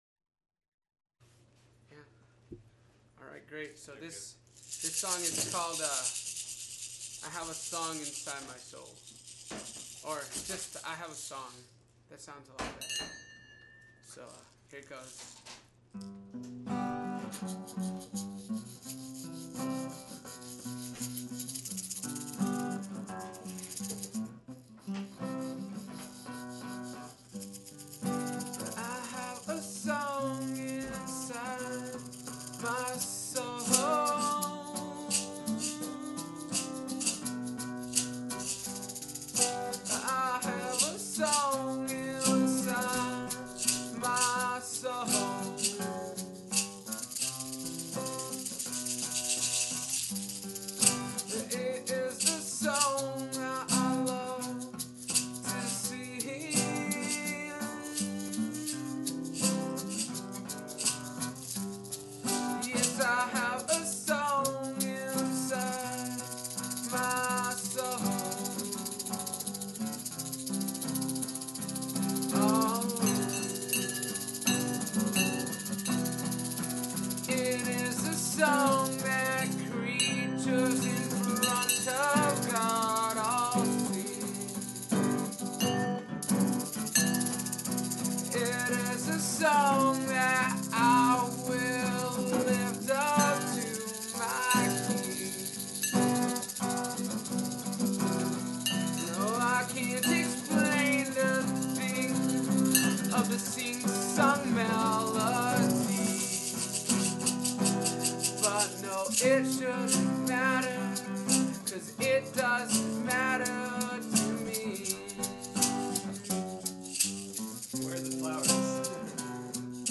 Hawthorne - thoughtful rock
we are the only piano rock band that features the five of us.